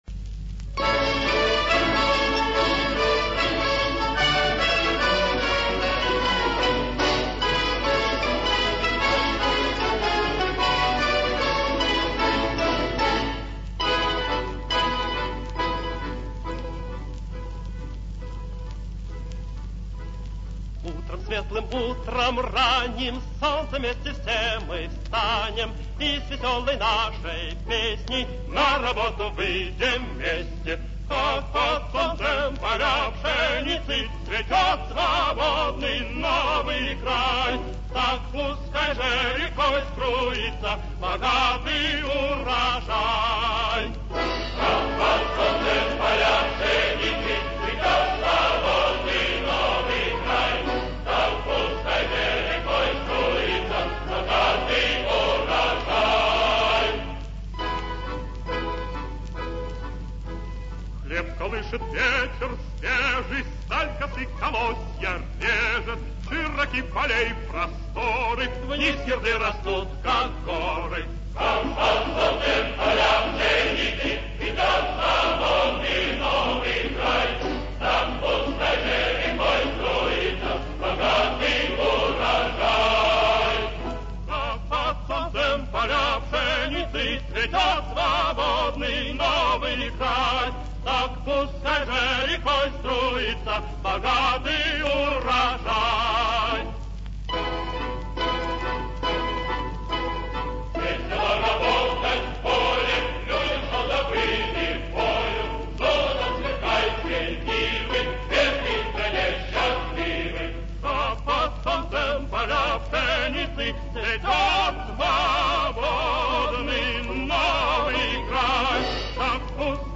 Песня венгерских хлеборобов